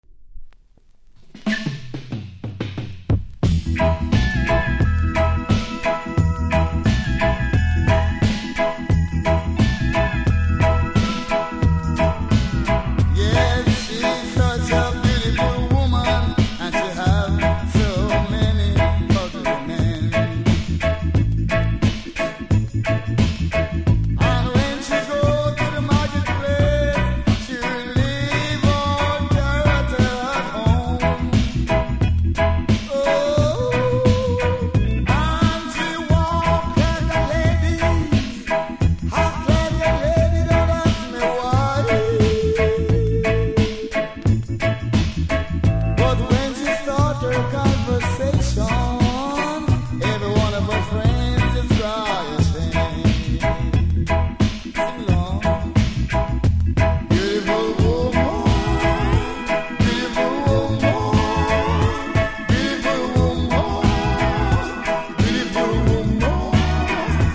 REGGAE 80'S
多少うすキズありますが音は良好なので試聴で確認下さい。